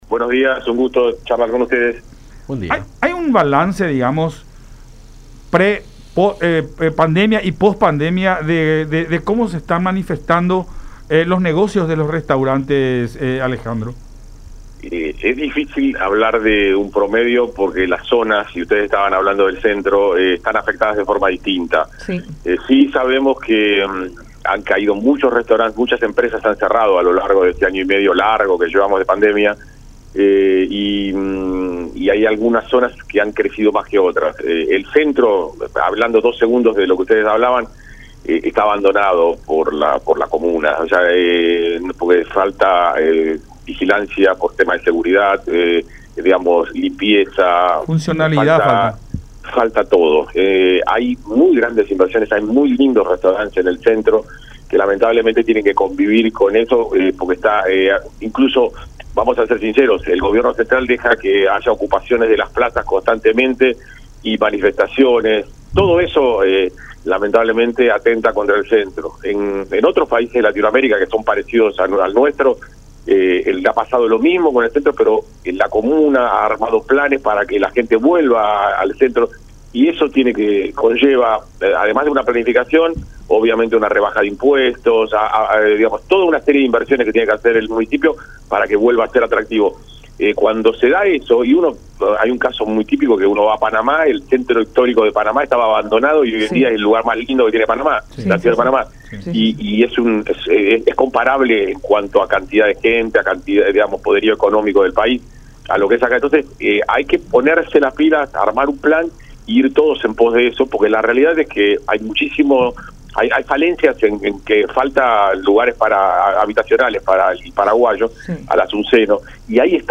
en diálogo con Enfoque 800 a través de La Unión